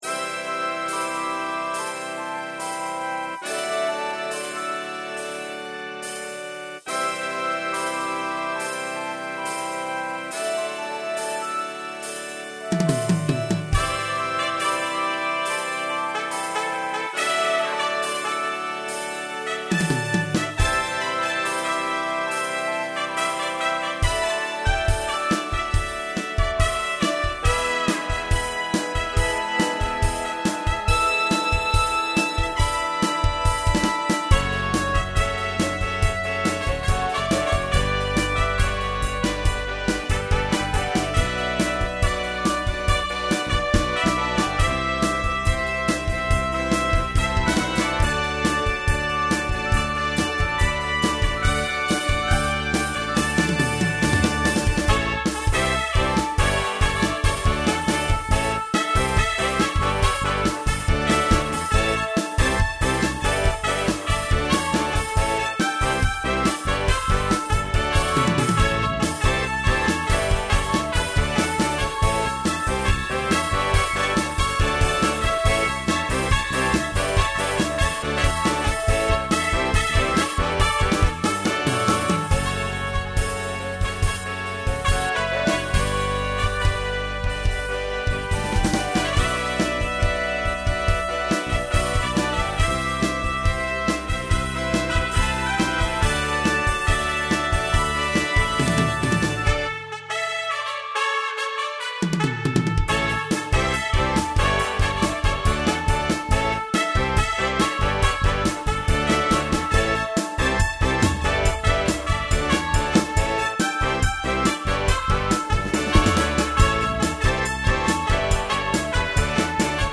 Here is the garageband version: